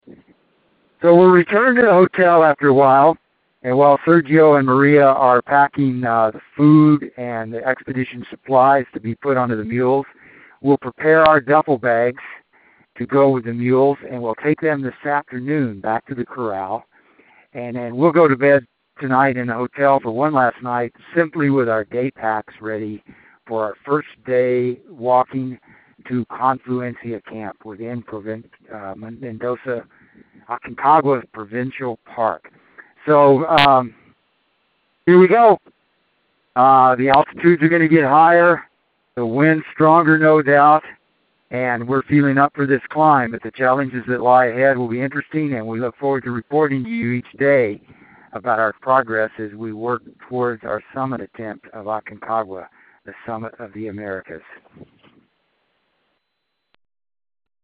Aconcagua Expedition Dispatch